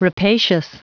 Prononciation du mot rapacious en anglais (fichier audio)
Prononciation du mot : rapacious